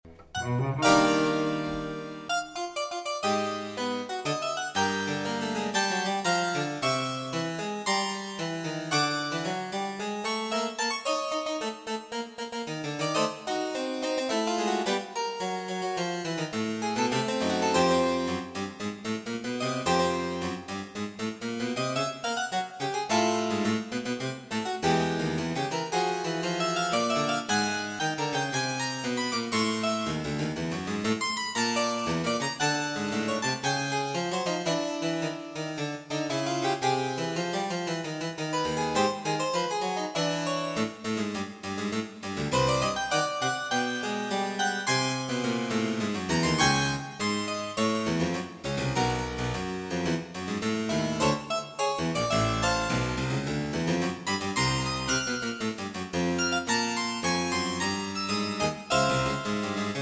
cello
baritone
soprano
synthesizer